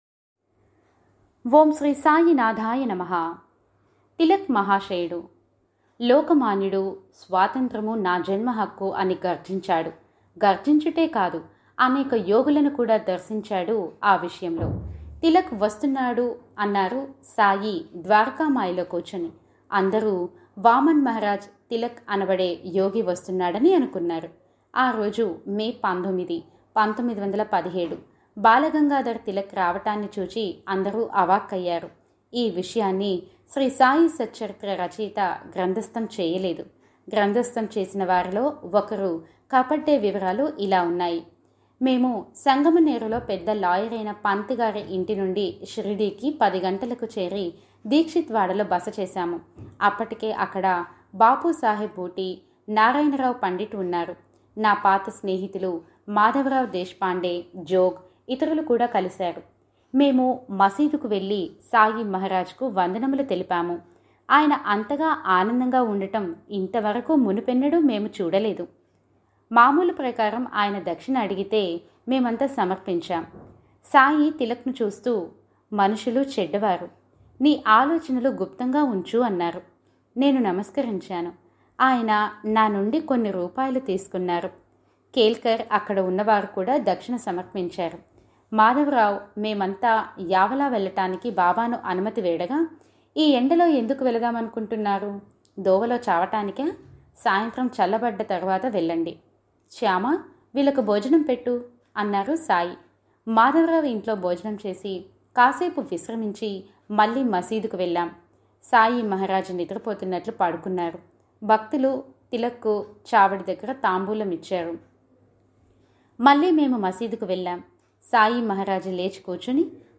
Voice Support By: